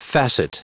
Prononciation du mot facet en anglais (fichier audio)
Prononciation du mot : facet